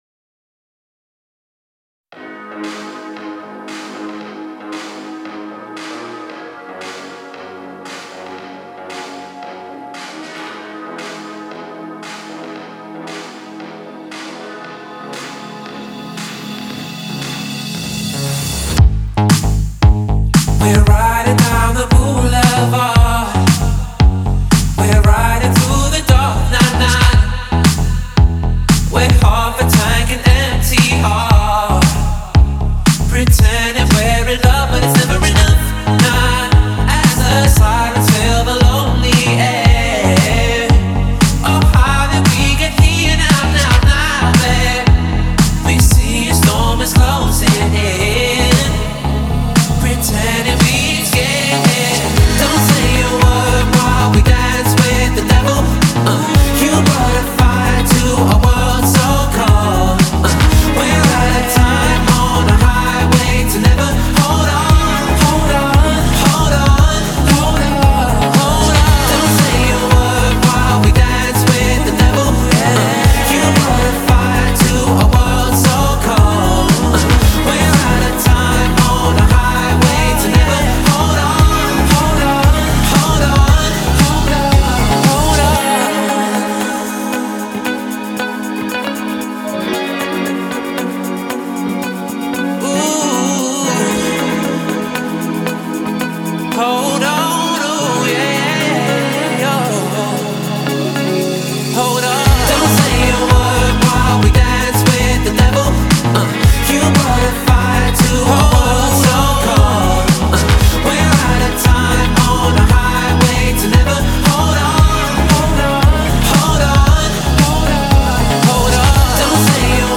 BPM115